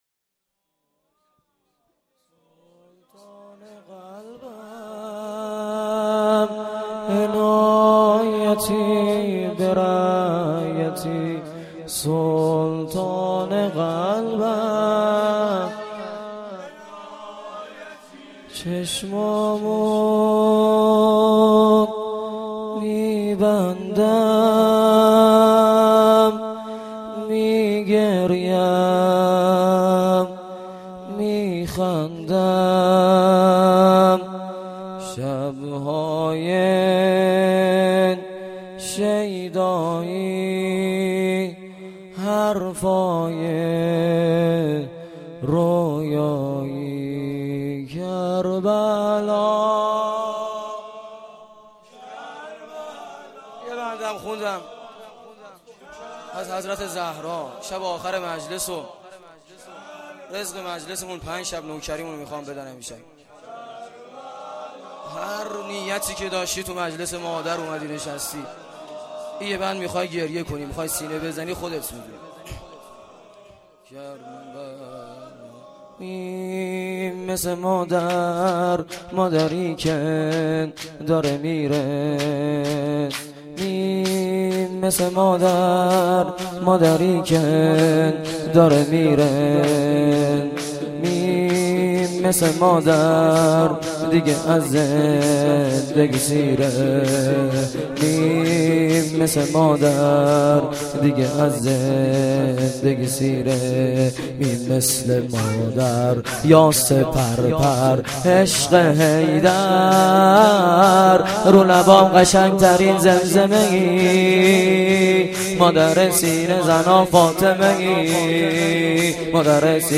فاطمیه92(شب پنجم)